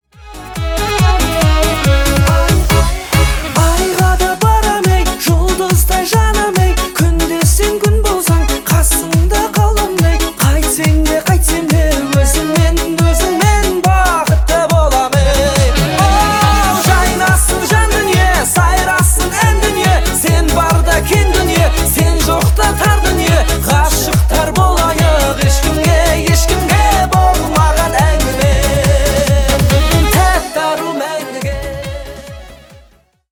Казахские
весёлые